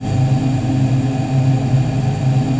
VOICEPAD20-LR.wav